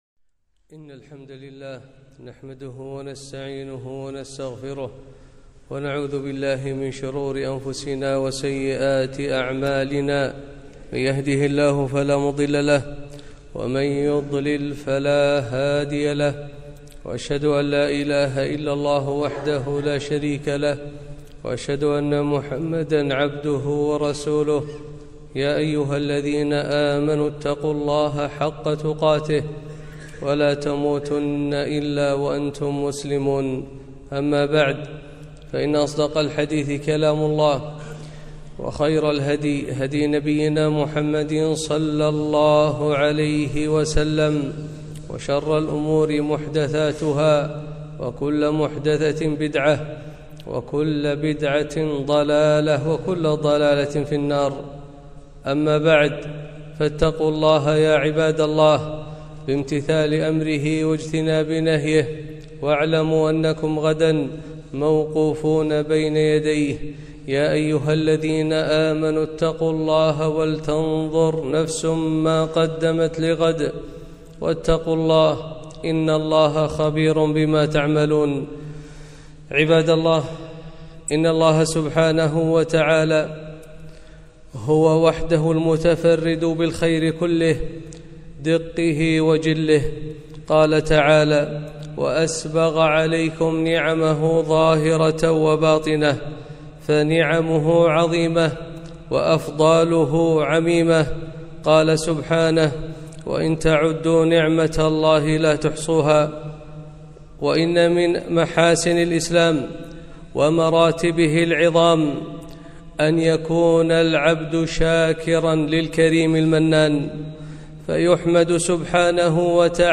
خطبة - شكر النعم